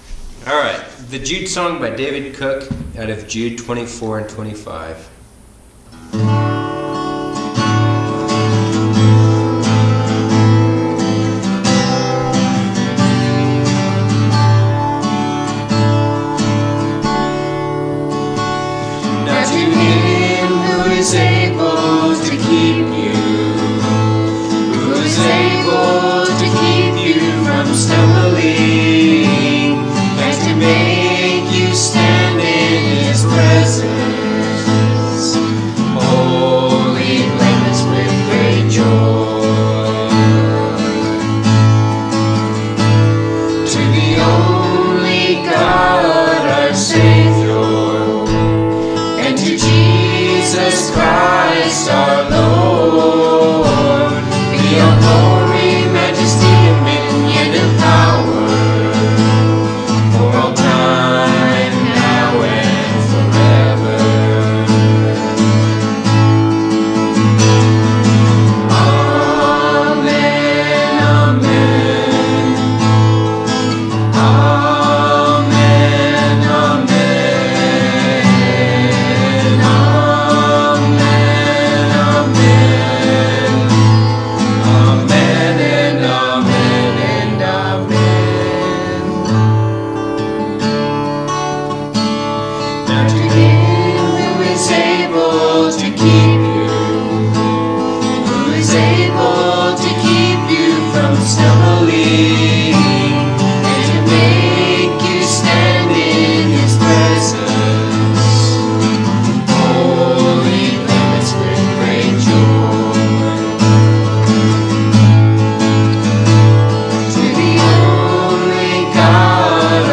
4/5 – Hymns
April-5-Hymns.mp3